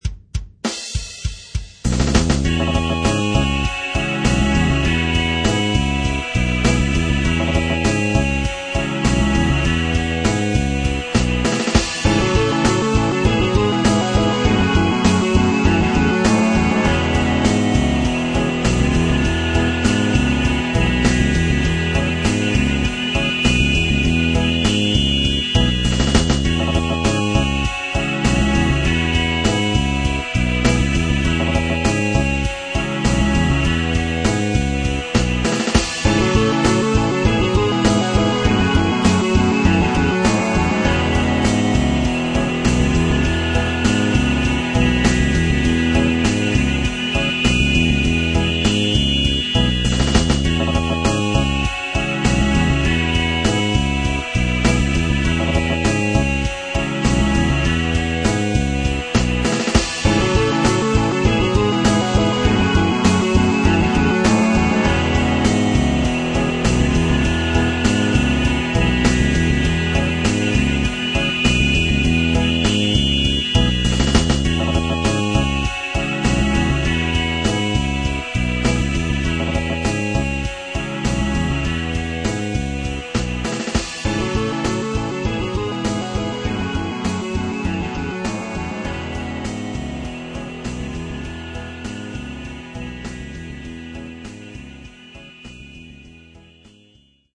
- (lo-bandwidth / mono) - will pop up in a new window
For the record, nobody played anything on this tune - it's all sampled and scored in Reason.